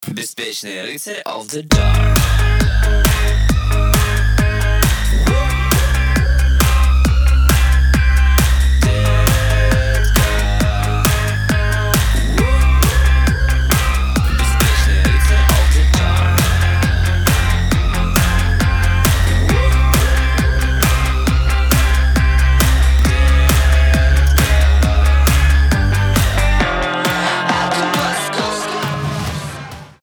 альтернатива
поп-панк
dance rock